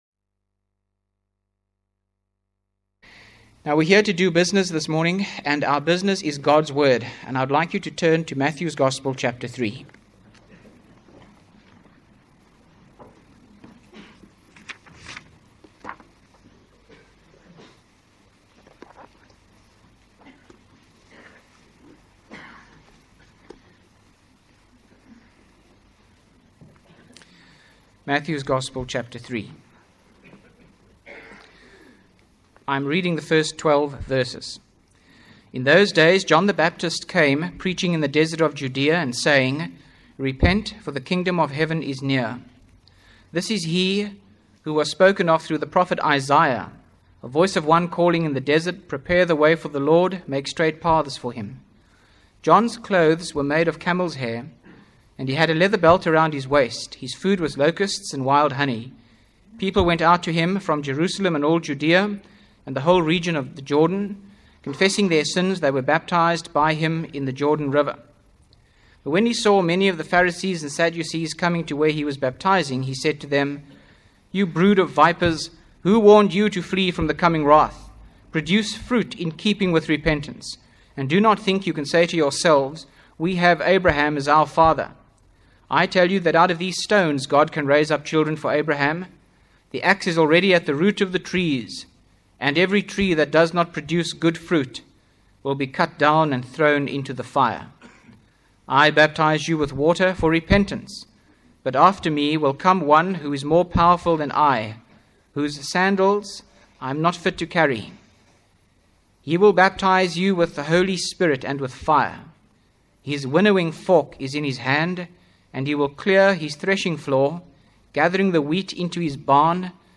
by Frank Retief | Jan 28, 2025 | Frank's Sermons (St James) | 0 comments